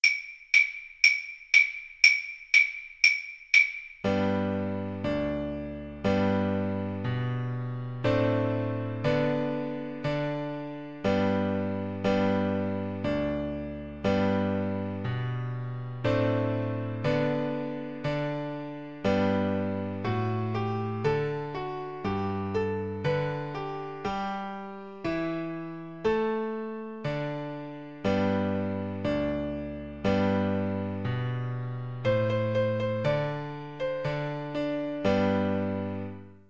4/4 (View more 4/4 Music)
Arrangement for Guitar solo in notation and tab formats.
G major (Sounding Pitch) (View more G major Music for Guitar )
Guitar  (View more Easy Guitar Music)
Traditional (View more Traditional Guitar Music)
deck_the_hallsGUIT_kar1.mp3